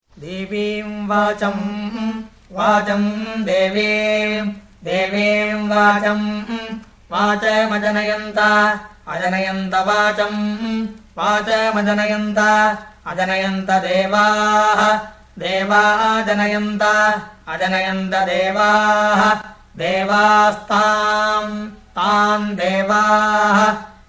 Vikrti Recitation
00-veda2-vkrti-mala.mp3